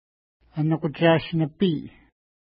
ID: 454 Longitude: -62.1555 Latitude: 53.2648 Pronunciation: ənukutʃa:ʃ-nəpi: Translation: Squirrel Lake Feature: lake Explanation: This name is recent.